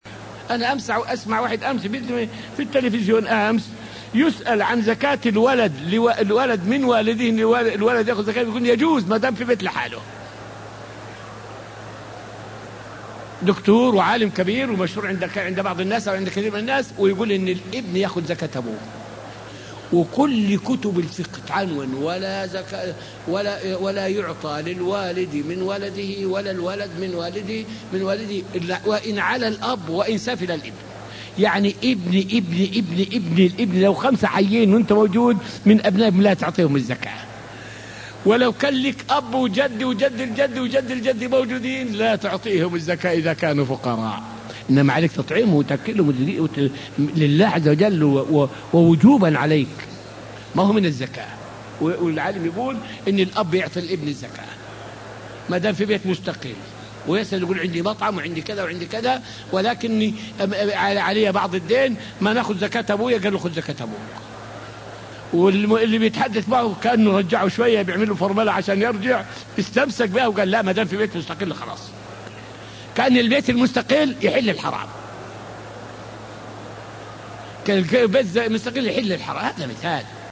فائدة من الدرس الخامس والعشرون من دروس تفسير سورة البقرة والتي ألقيت في المسجد النبوي الشريف حول معنى قوله تعالى {ولا تواعدوهن سرًا}.